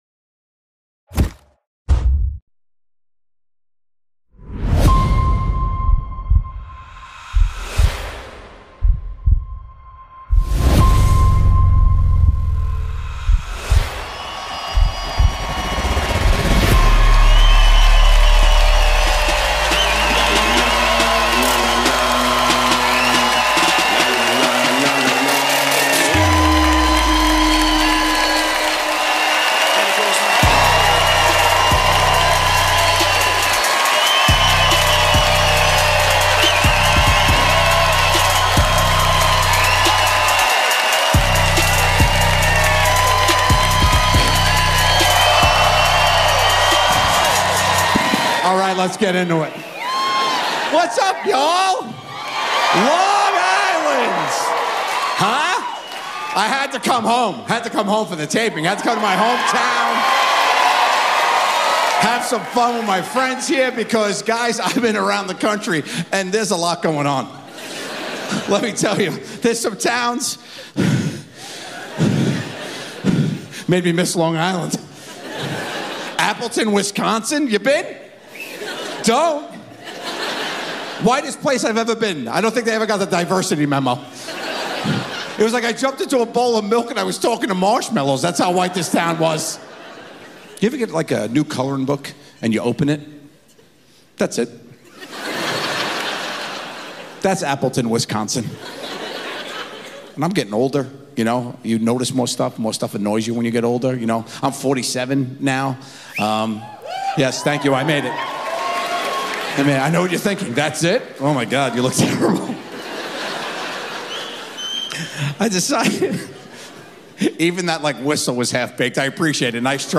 Joe Gatto | Messing With People (Full Comedy Special) 🤣